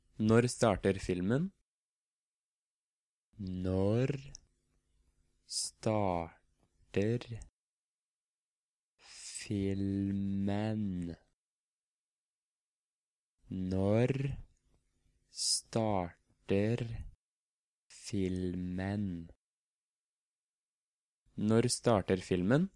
Practice Norwegian pronunciation by listening to sentences in normal tempo and then very slowly, so that you can hear the different sounds.